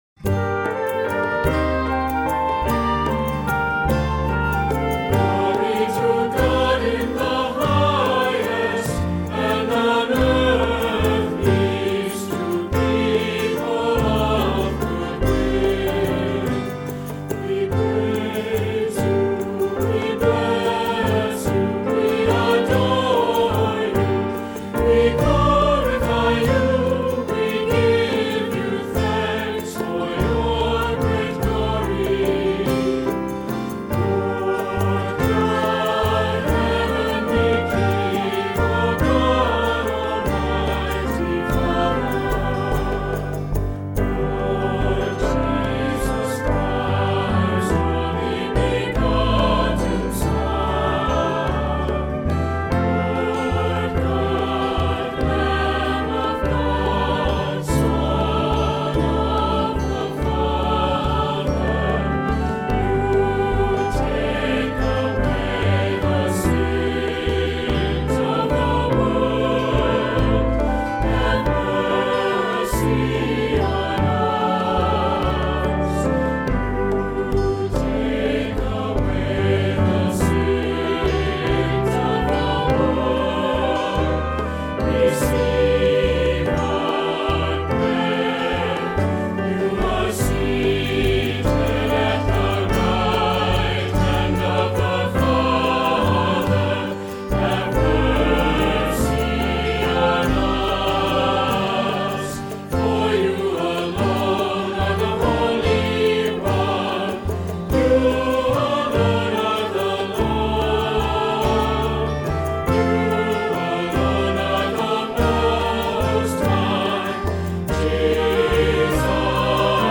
Voicing: SAB; Assembly